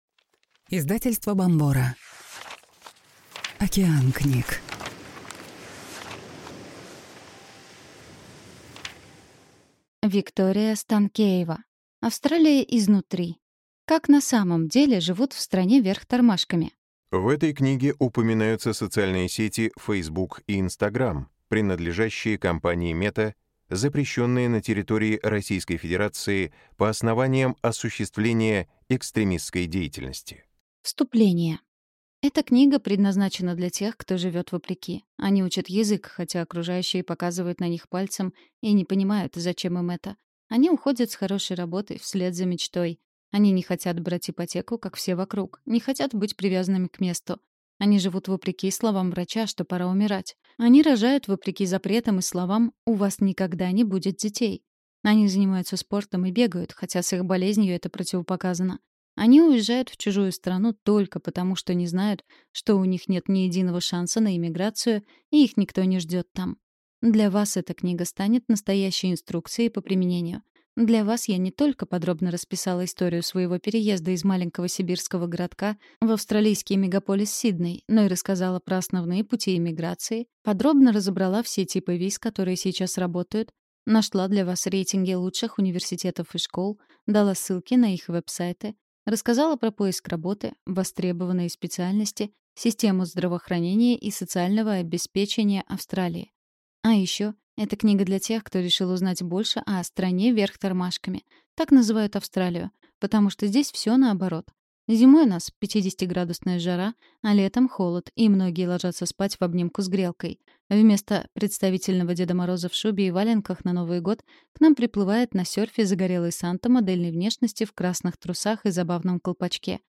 Аудиокнига Австралия изнутри. Как на самом деле живут в стране вверх тормашками?